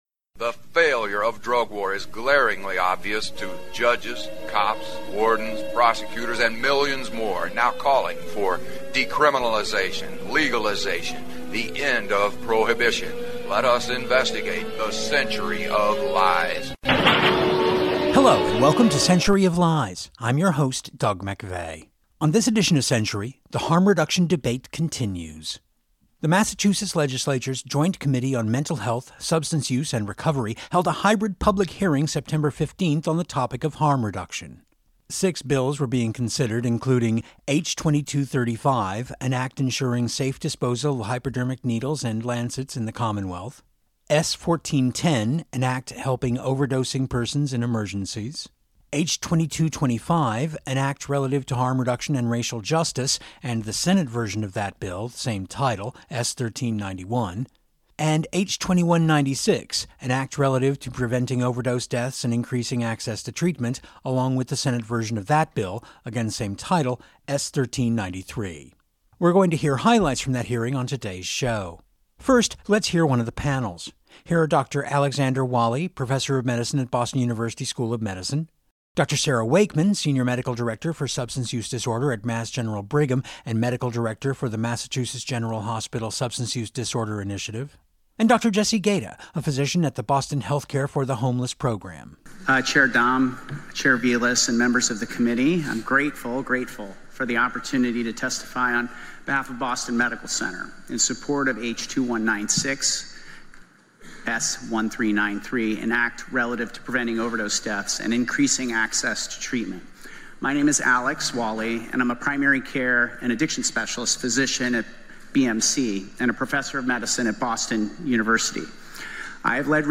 The Massachusetts Legislature’s Joint Committee on Mental Health, Substance Use and Recovery held a hybrid public hearing September 15 on the topic of harm reduction.